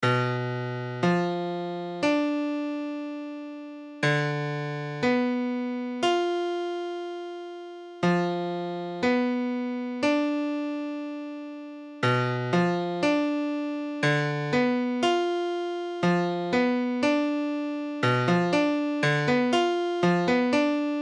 Tablature Cbdim.abcCbdim : accord de Do bémol diminué
Mesure : 4/4
Tempo : 1/4=60
Forme fondamentale : tonique quinte diminuée tierce mineure
W:Premier renversement : tierce mineure octave quinte diminuée |
W:Second renversement : quinte diminuée octave tierce mineure |
Cbdim.mp3